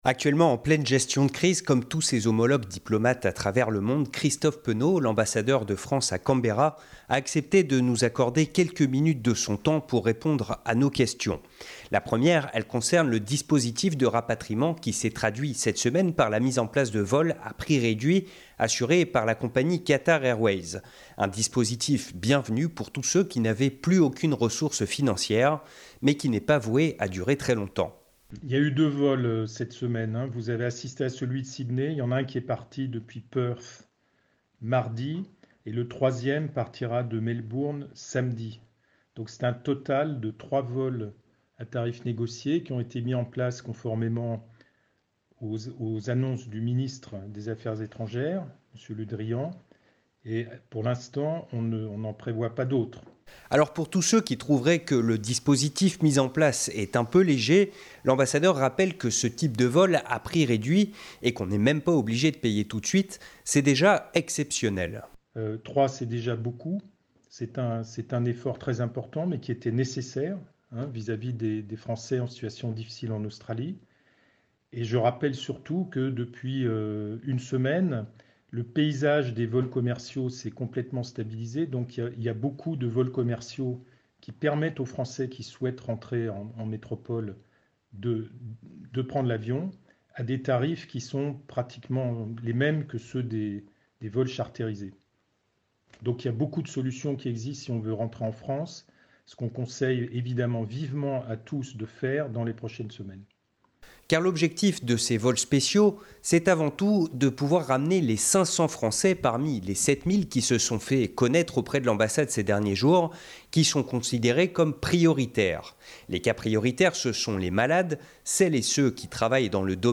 Christophe Penot, l'ambassadeur de France à Canberra, nous explique le dispositif de rapatriement mis en place cette semaine, les négociations qu'il assure avec les autorités australiennes et surtout conseille aux Français toujours sur le territoire de rentrer au pays tant qu'il y a encore des avions assurant des liaisons internationales.